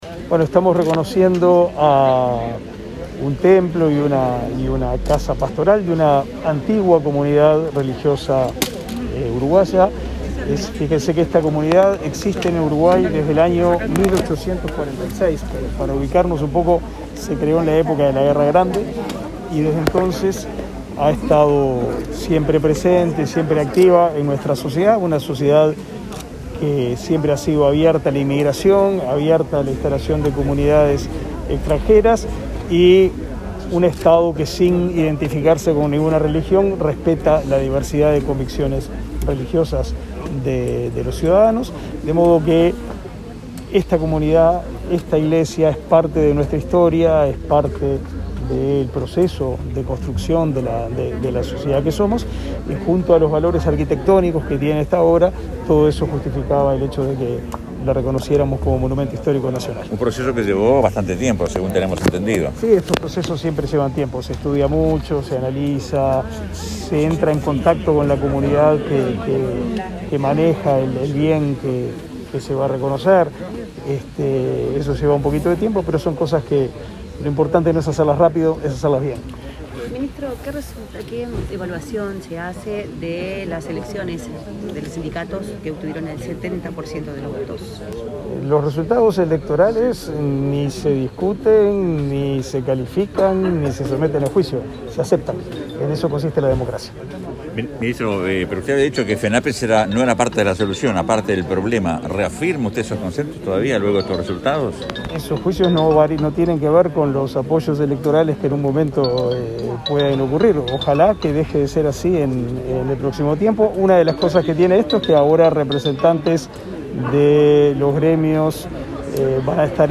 Declaraciones a la prensa del ministro de Educación y Cultura, Jorge da Silveira
Declaraciones a la prensa del ministro de Educación y Cultura, Jorge da Silveira 01/11/2021 Compartir Facebook X Copiar enlace WhatsApp LinkedIn Tras participar en el acto de declaración de Monumento Histórico Nacional al Templo y Casa Pastoral de la Congregación Evangélica Alemana, este 1 de noviembre, el ministro de Educación y Cultura, Jorge da Silveira, efectuó declaraciones a la prensa.